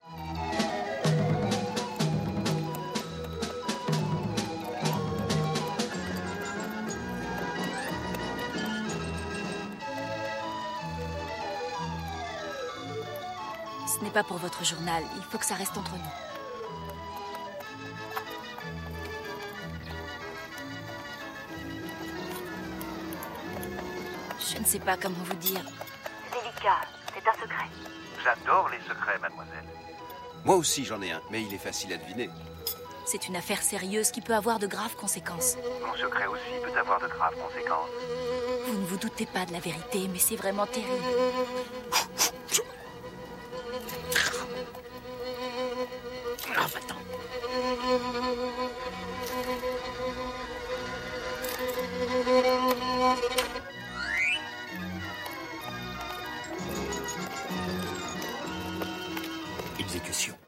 Orgue limonaire et percussions.